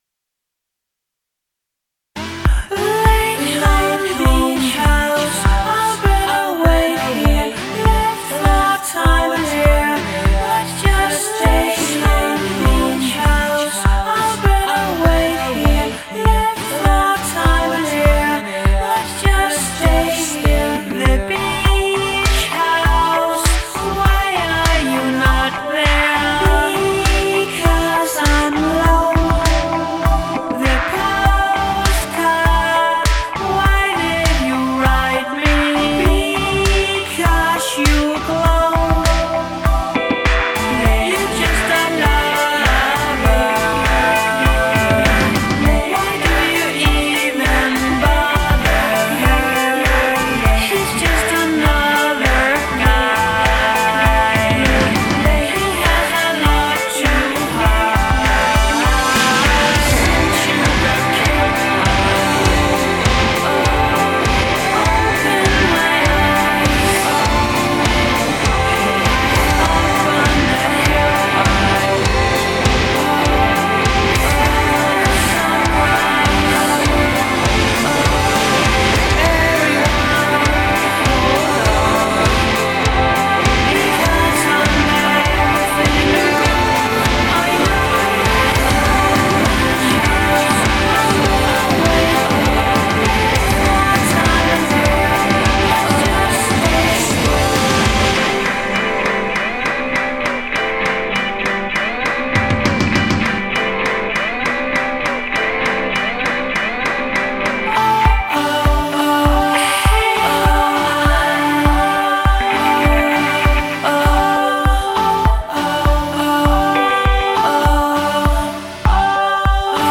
Here’s a bit of fun, engaging shoegazey stuff for you.
dream pop craftsman